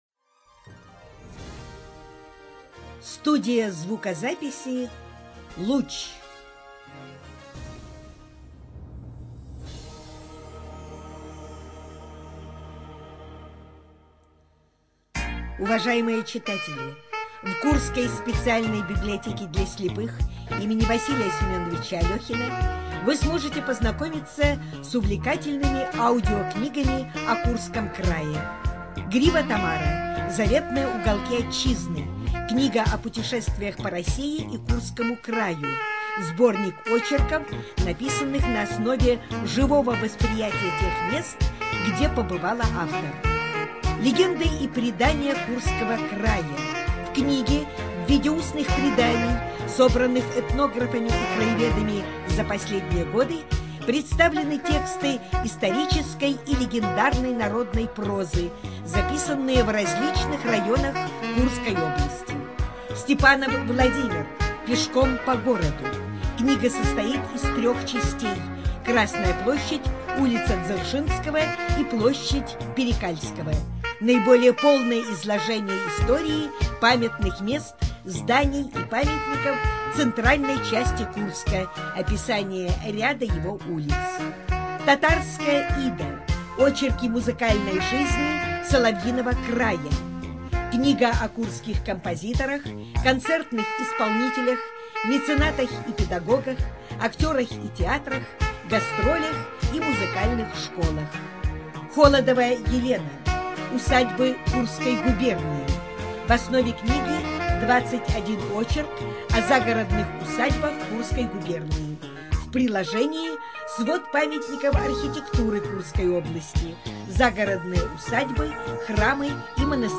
Студия звукозаписиКурская областная библиотека для слепых